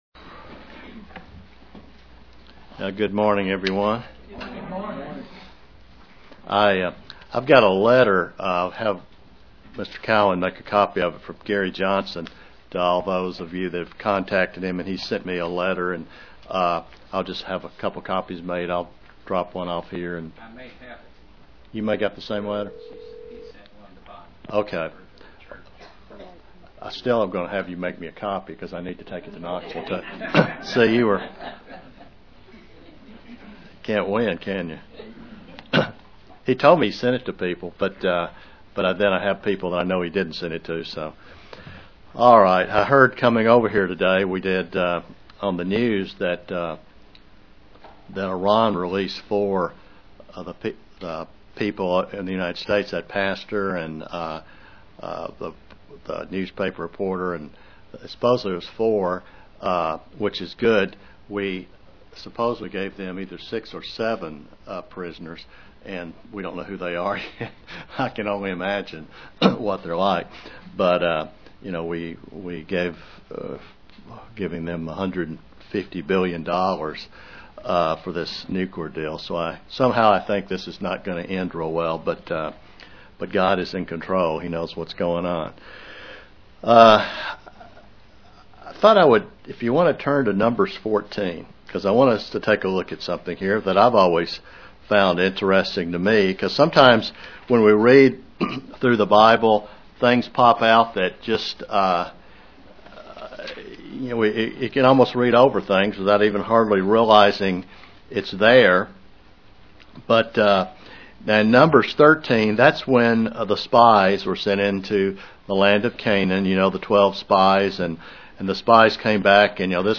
We can trace the ten times God tells Israel they turned their back on the promises of God up until the return of the spies in Numbers 14. (Presented to the London KY, Church)
Sermons